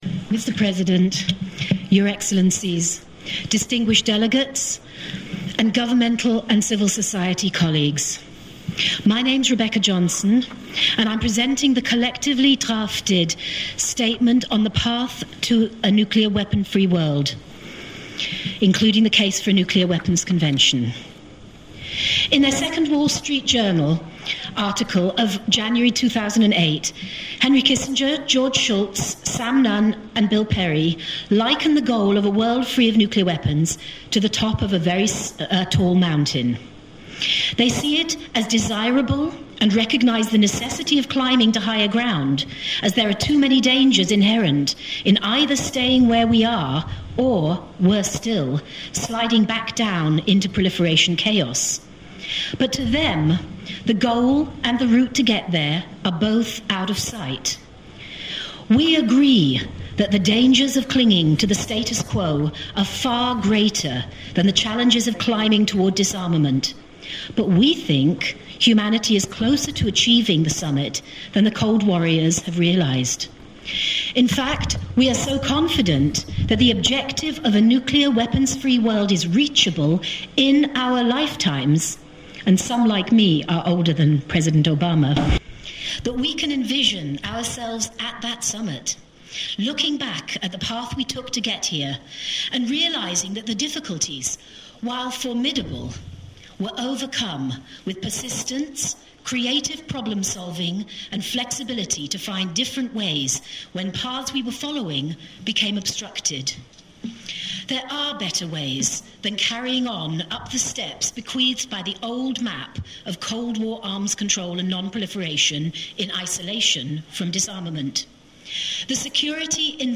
at the NGO Presentations to the NPT